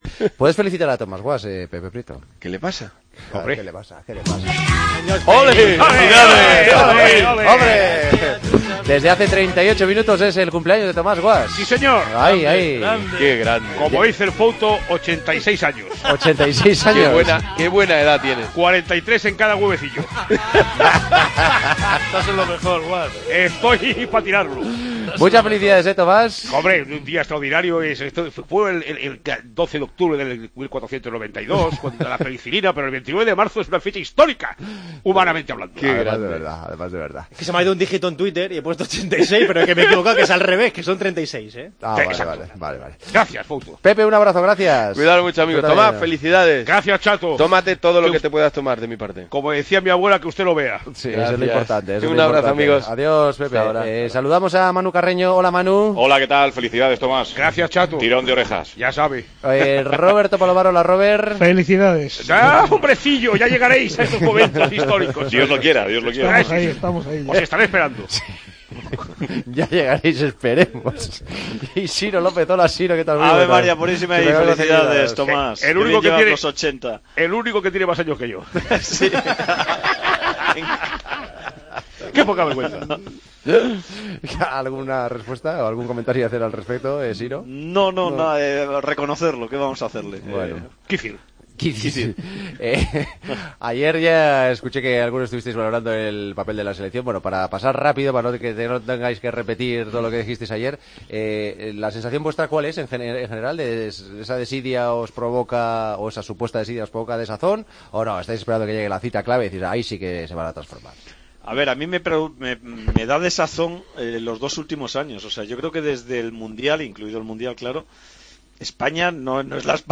Debate con Tomás Guasch